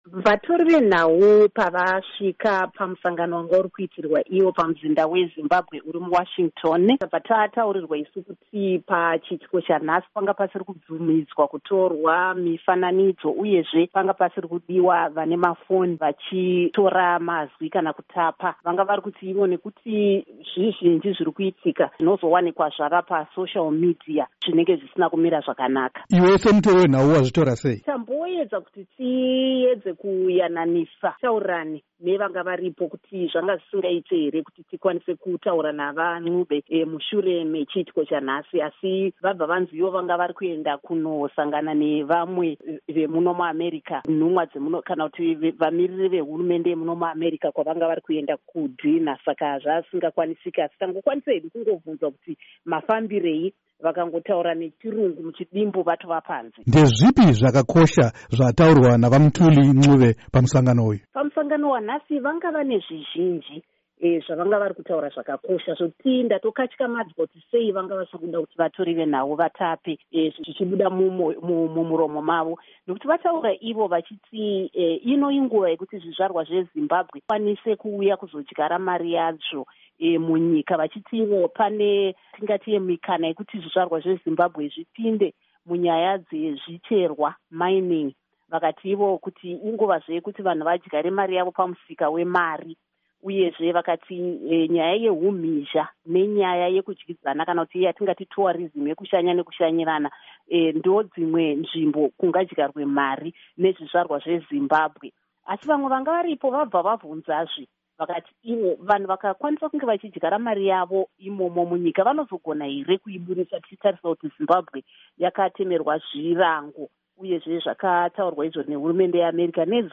Hurukuro naVaMthuli Ncube